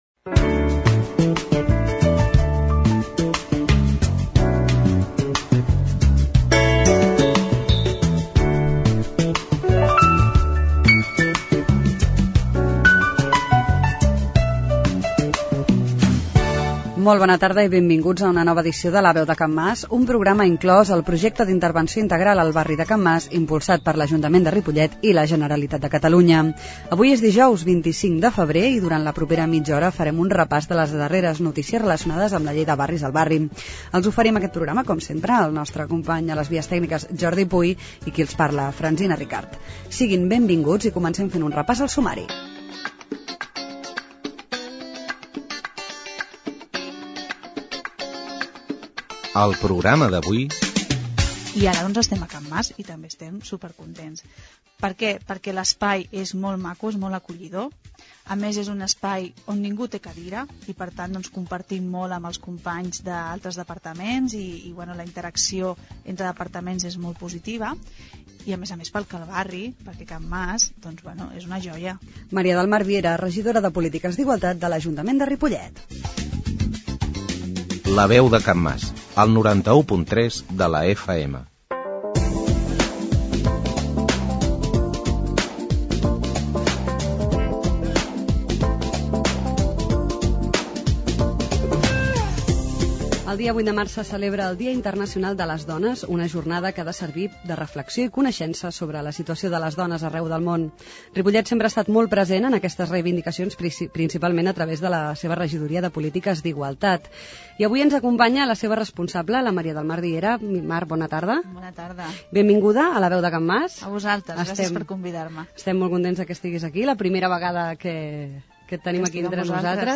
Comunicació La Veu de Can Mas: programa del 25 de febrer de 2010 -Comunicació- 25/02/2010 Al programa d'avui ens ha acompanyat la Maria del Mar Viera, regidora de Pol�tiques d'Igualtat de l'Ajuntament de Ripollet, per parlar del programa d'activitats del Dia de les Dones. A m�s, em fet un rep�s de les activitats que tindran lloc el dia 7 de mar�, al parc Primer de Maig, en el marc de la Mostra de programes de la Llei de Barris. La Veu de Can Mas �s un programa de r�dio incl�s en el Projecte d'Intervenci� Integral al barri de Can Mas, que s'emet el darrer dijous de mes, de 19 a 19.30 hores i en redifusi� diumenge a les 11 del mat�.